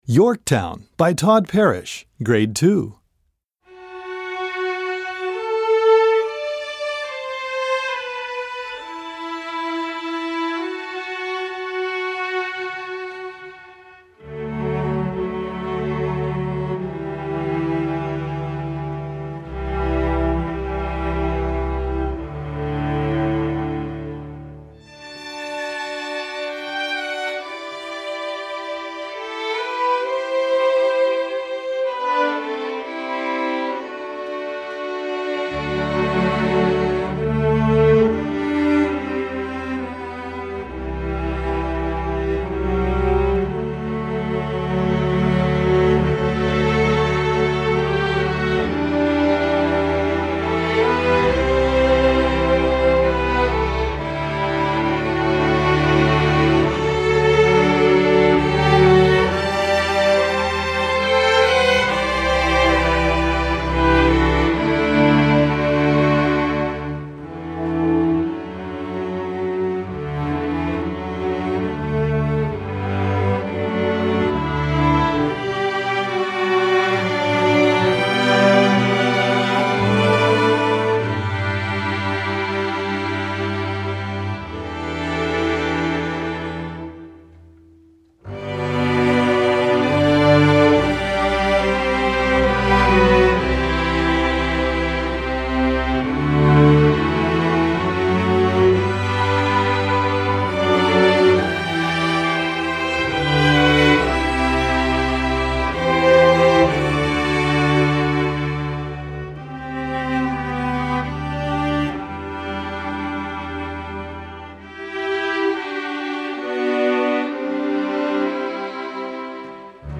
Gattung: Streichorchester
Besetzung: Streichorchester